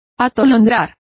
Complete with pronunciation of the translation of bewilder.